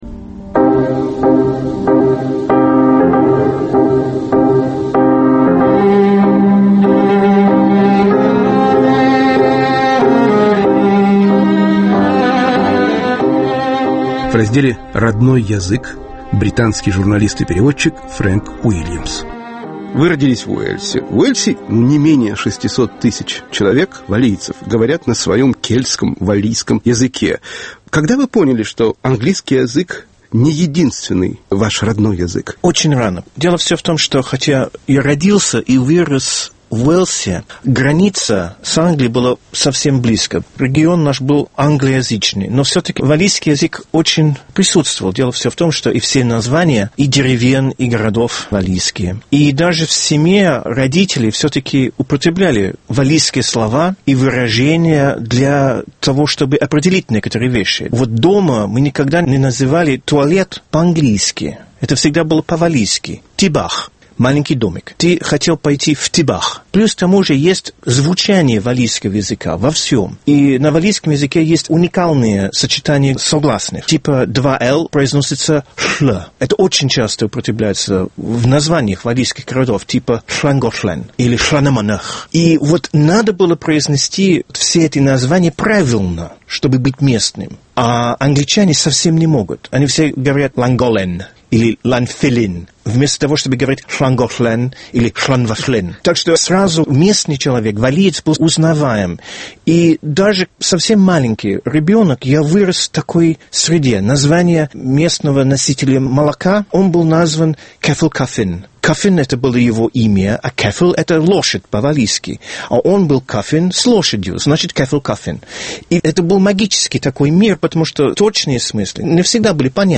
"Родной язык" Разговор